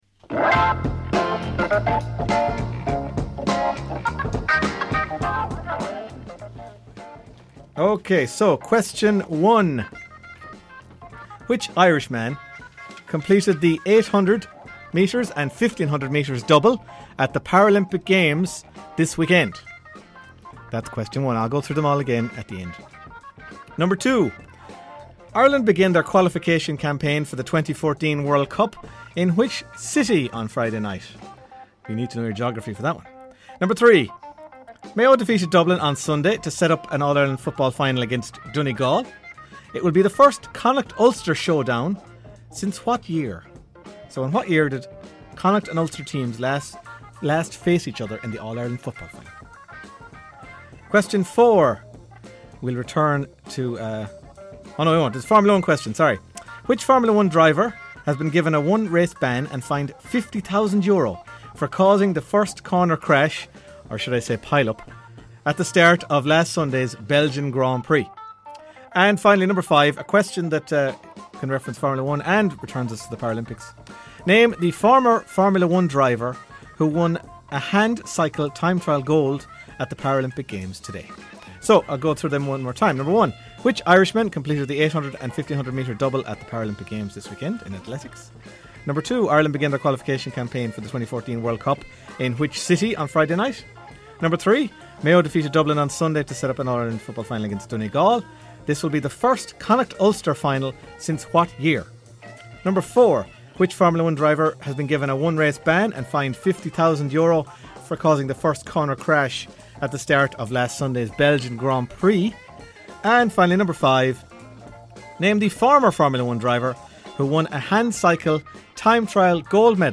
Five sports trivia questions from the 'Half-time Team Talk' show on Claremorris Community Radio.